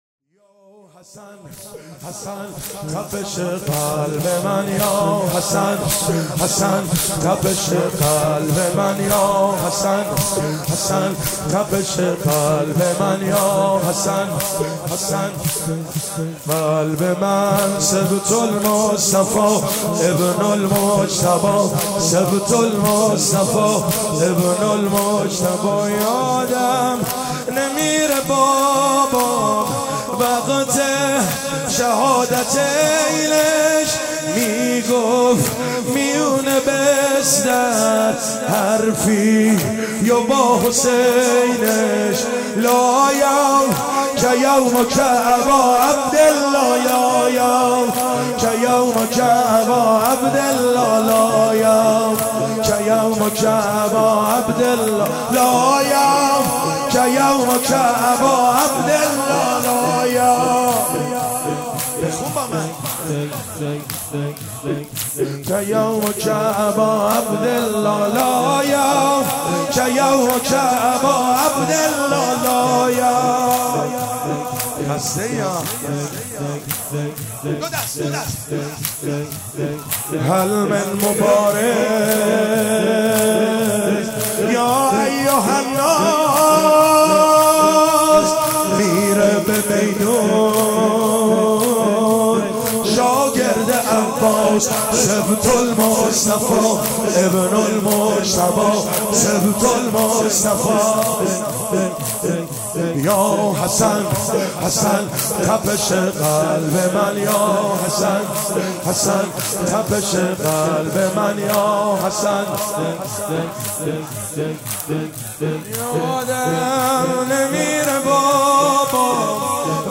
شب ششم محرم/95 هیات قمر بنی هاشم